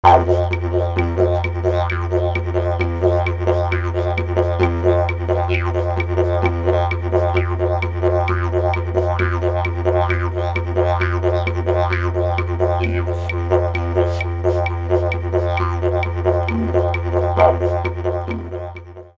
Practice Rhythms to Develop Your Technique
CLICK HERE fast diaphragm rhythm